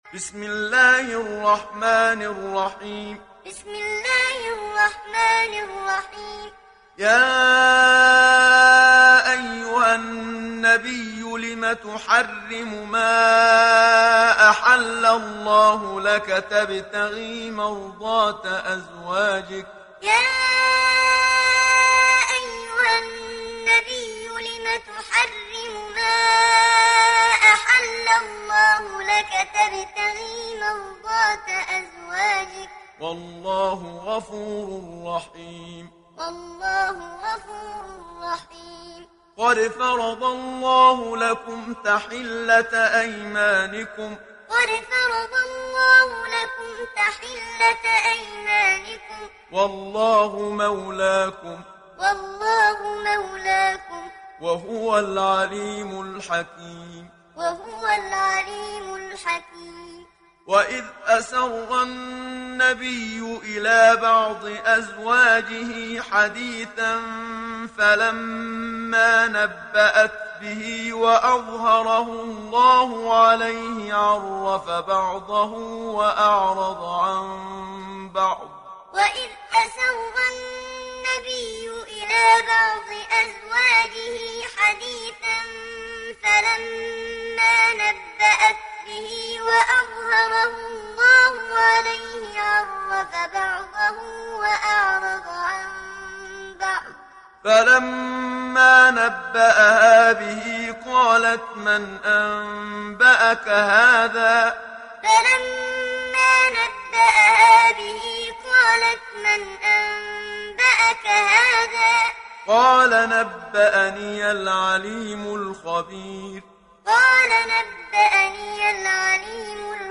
دانلود سوره التحريم محمد صديق المنشاوي معلم